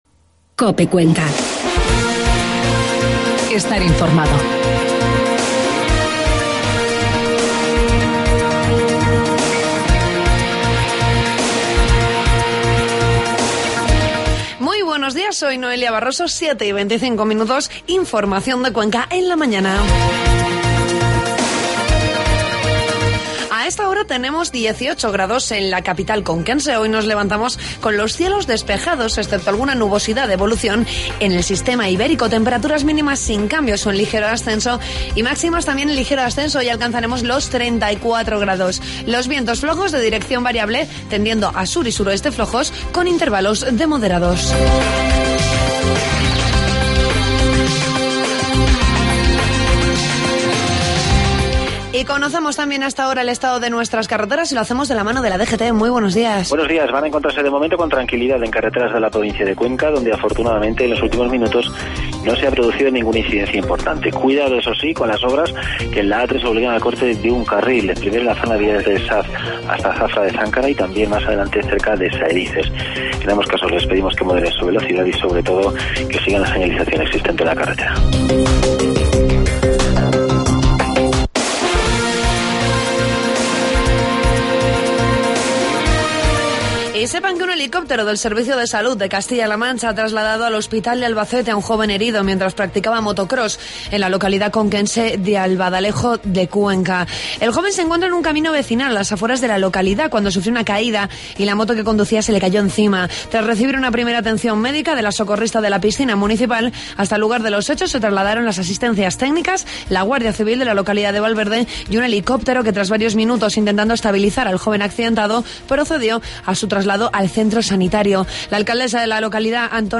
Informativo matinal 25 de julio